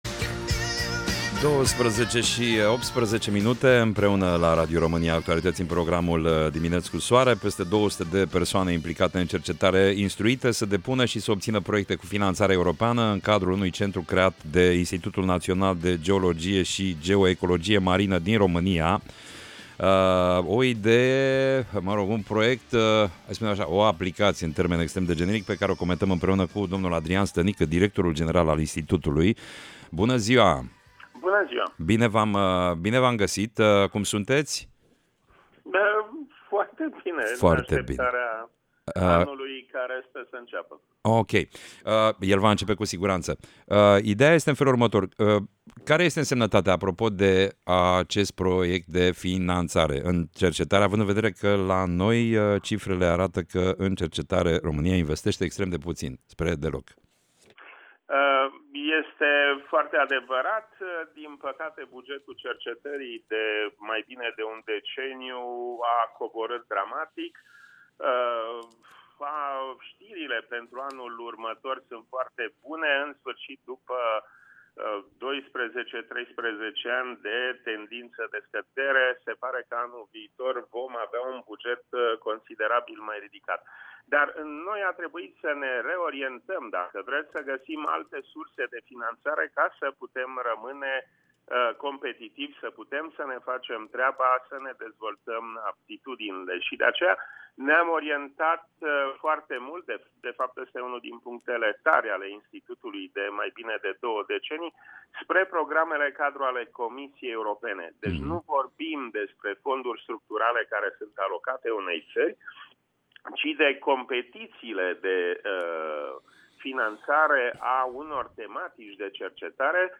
Interviu Radio Romania Actualitati